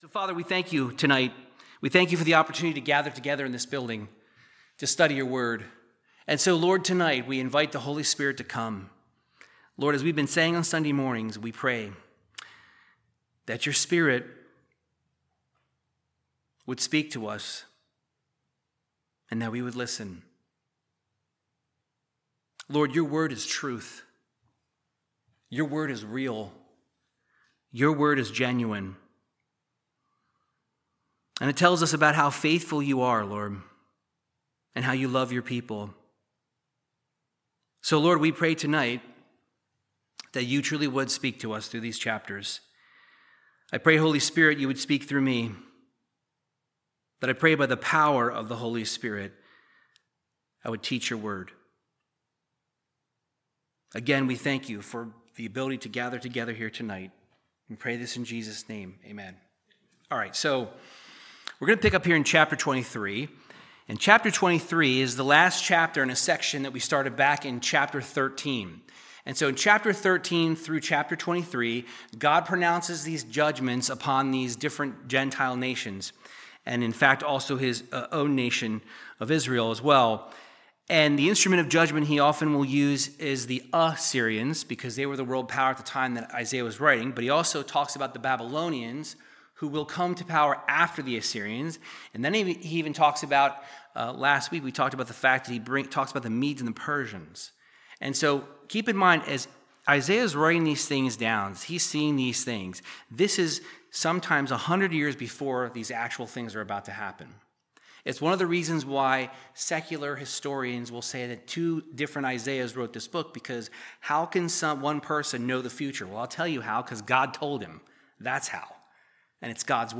Verse by verse Bible teaching in the book of Isaiah chapters 23 through 24